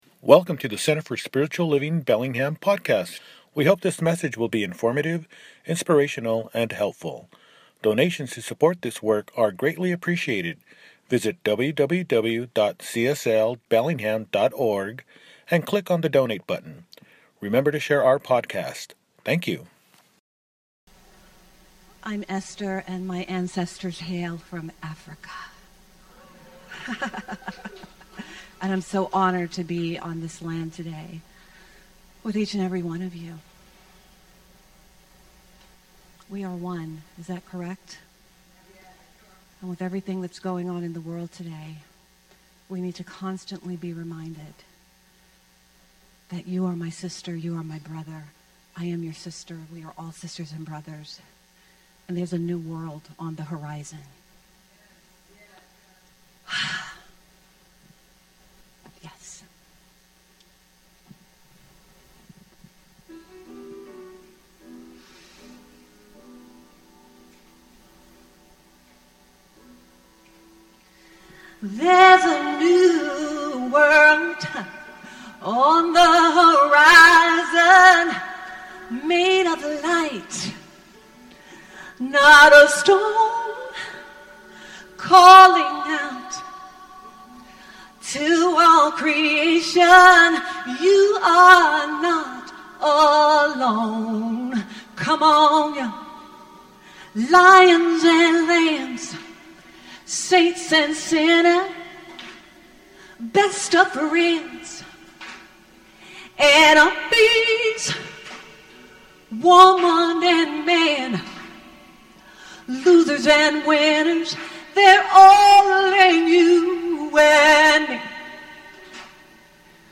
The Roadmap Home to Your Authentic Self – Second Service